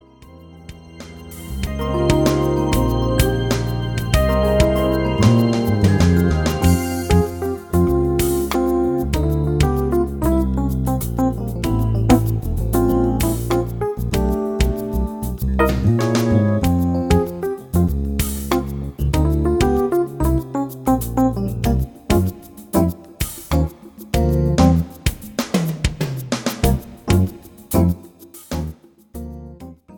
jazz and contemporary music